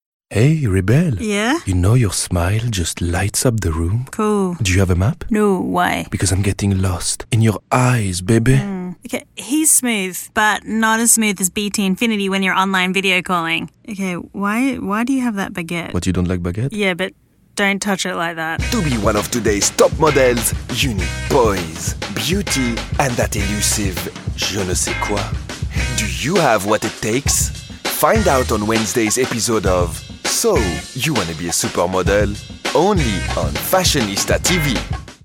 French-Belgian Voices
French-Belgian, Male, Home Studio, 20s-30s
Home Studio Read